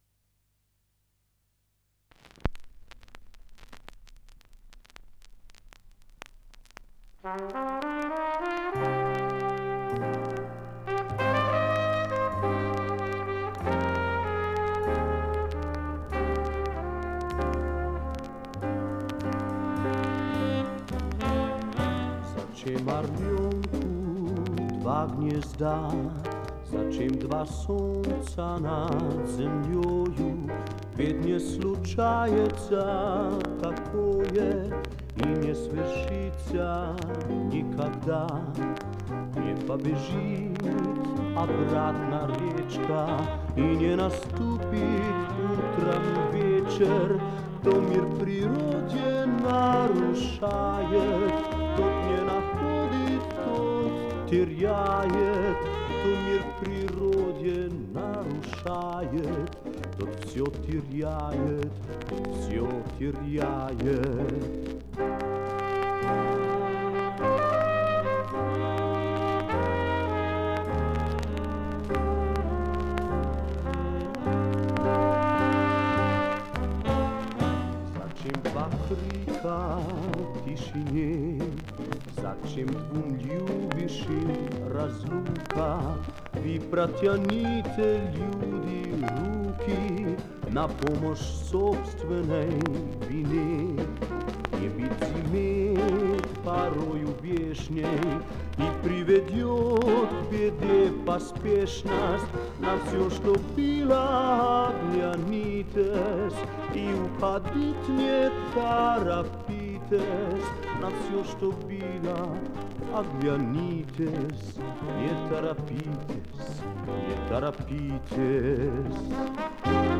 Мозилла и Макстон крутят нормально с раритетными скрипами.